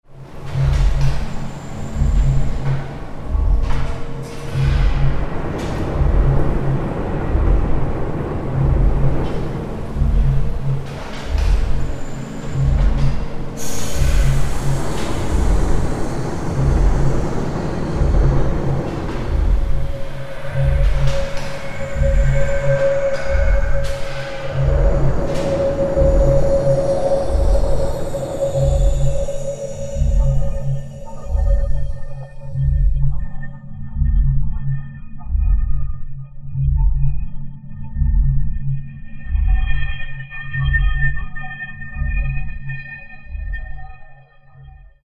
Site Specific Music for Eight Channel Tape and Steam Engines